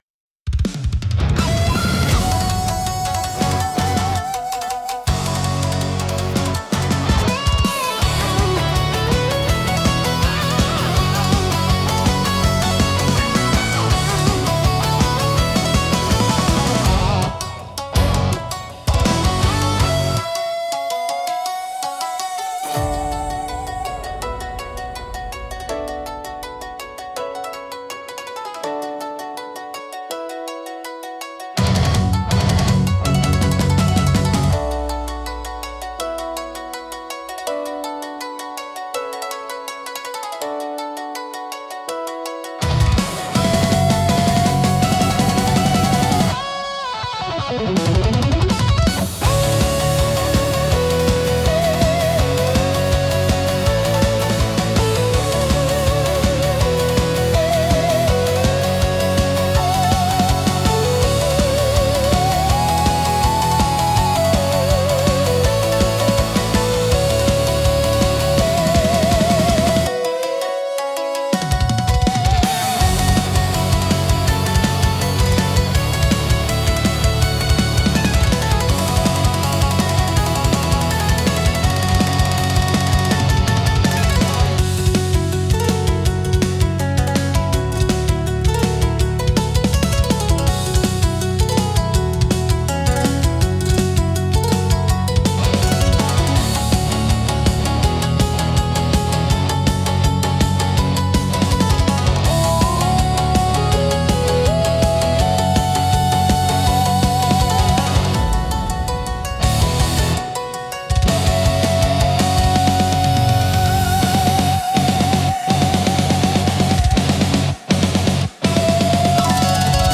尺八の幽玄な響きと琴の繊細な調べが、重厚なギタートーンや高速ビートと絡み合い、独自の緊張感とダイナミズムを生み出します。
聴く人に力強さと神秘性を同時に感じさせ、日本古来の精神と現代のエネルギーを融合したインパクトを与えます。